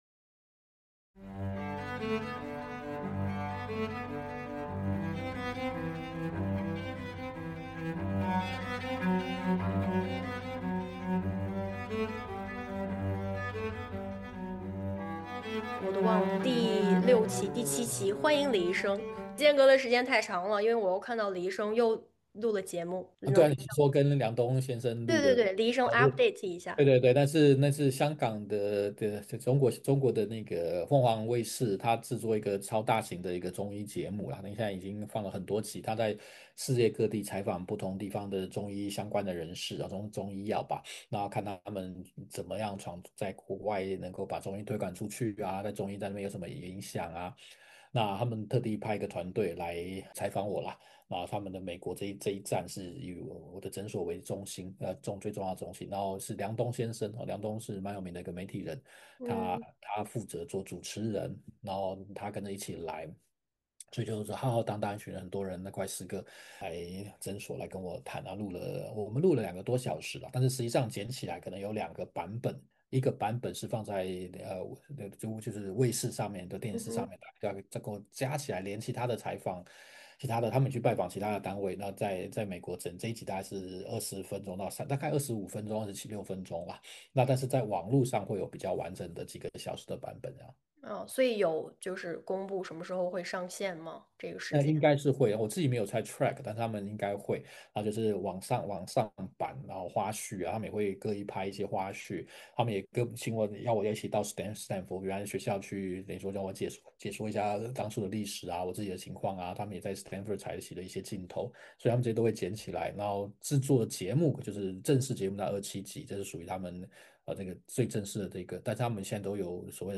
目前沒有特定的題材，也沒有特定的時間表，隨性也隨時間，藉由主持人的提問，來和大家聊一聊。